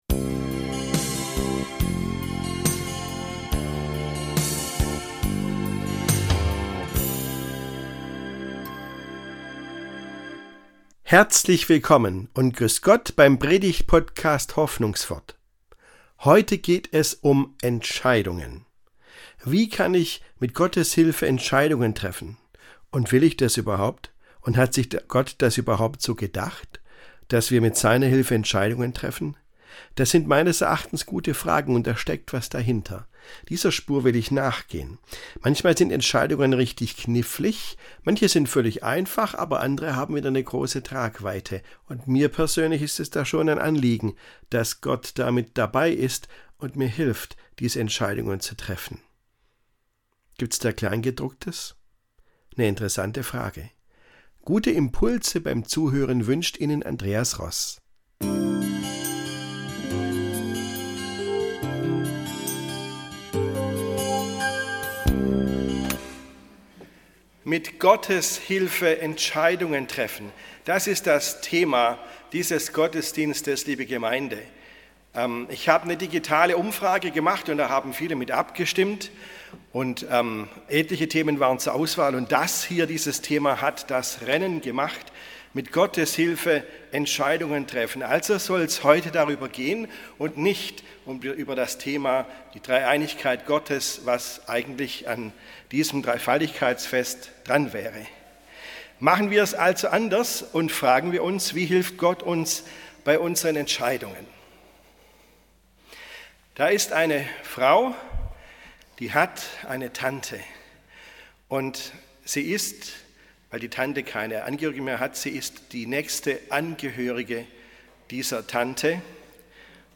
Mit Gottes Hilfe Entscheidungen treffen ~ Hoffnungswort - Predigten